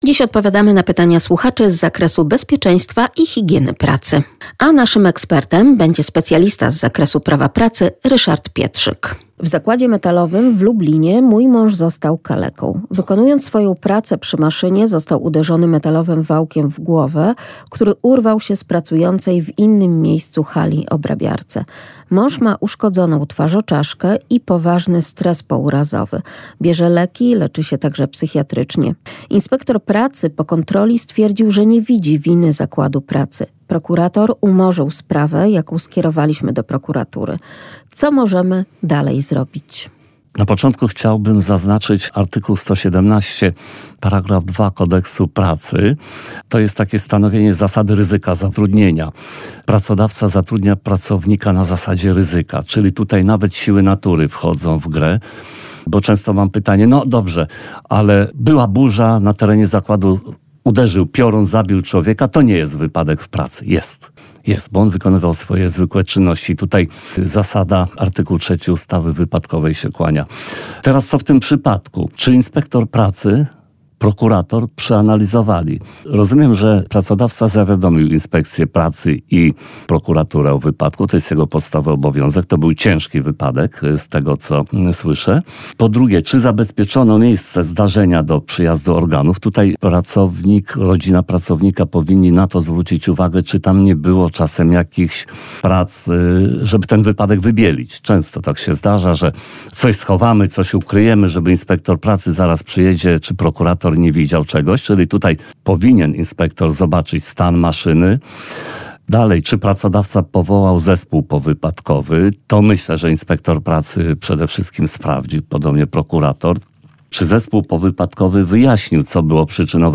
Dziś odpowiadamy na pytania Słuchaczy z zakresu bezpieczeństwa i higieny pracy.